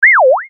/ F｜演出・アニメ・心理 / F-10 ｜ワンポイント マイナスイメージ_
間抜けなイメージ
ピヨウ